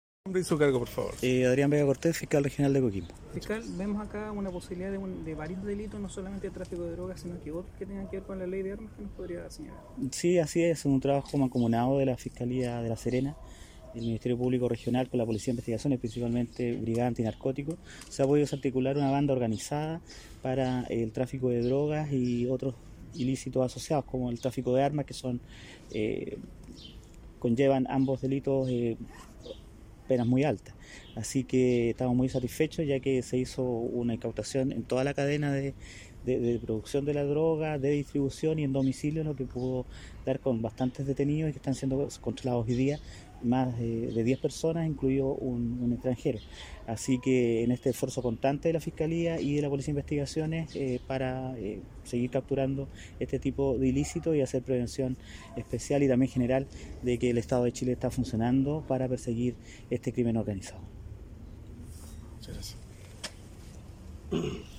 FISCAL-REGIONAL-1.mp3